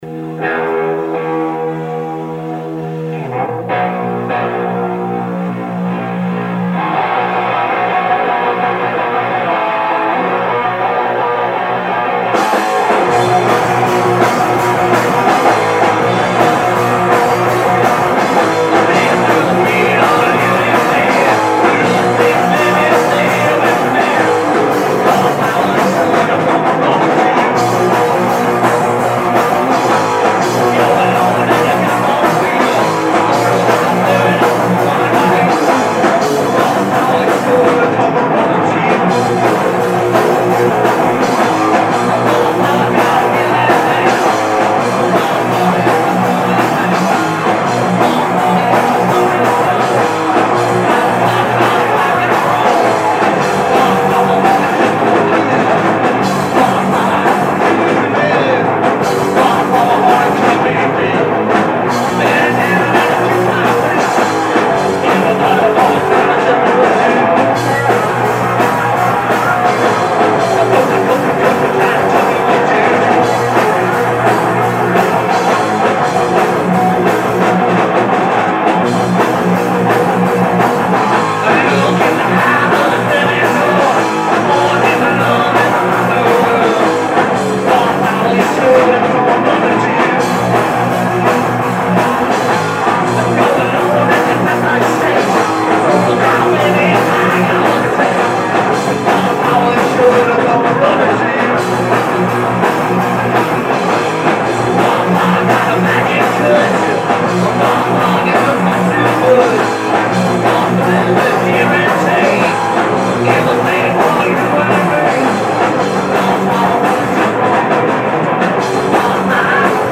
Hardcore
guest guitarist